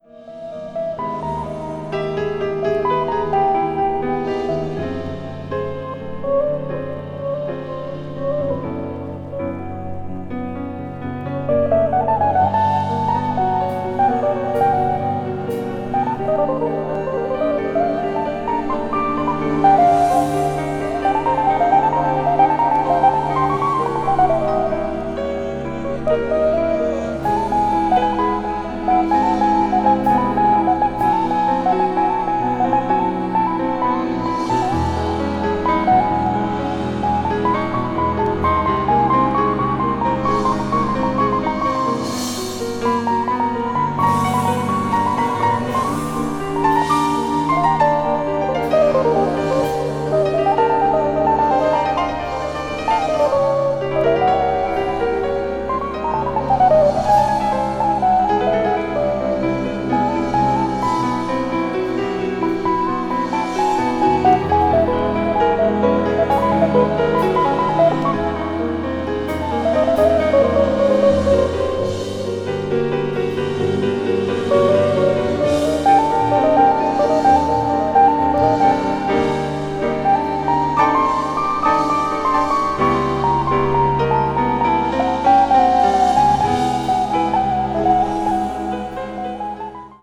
media : EX/EX(わずかにチリノイズが入る箇所あり)
contemporary jazz   deep jazz   spritual jazz